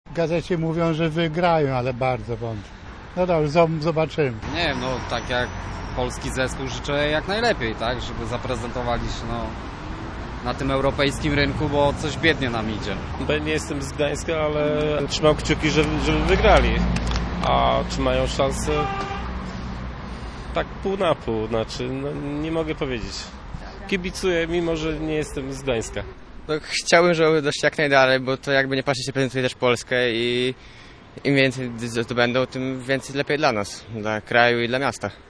– To polski zespół, więc życzę im jak najlepiej na tym europejskim rynku, bo jakoś biednie nam idzie – mówił gdańszczanin w rozmowie z naszą reporterką.